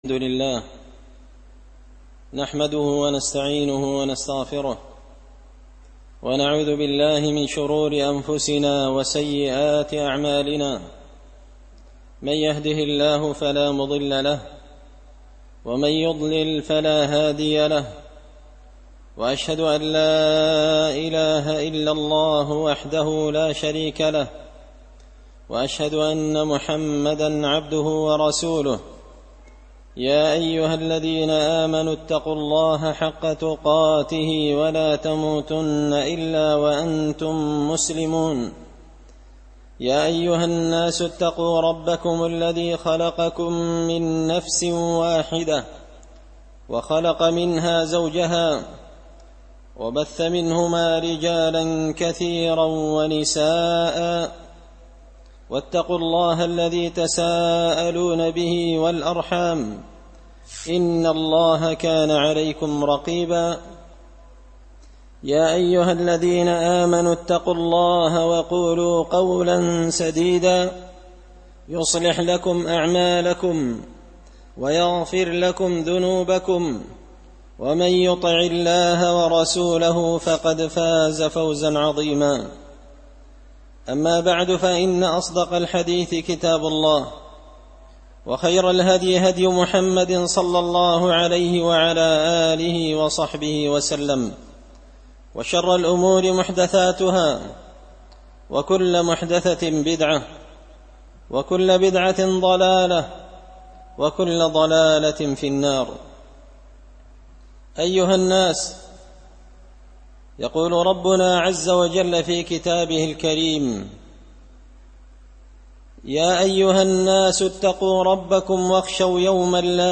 خطبة جمعة بعنوان – الغرور بالدنيا
دار الحديث بمسجد الفرقان ـ قشن ـ المهرة ـ اليمن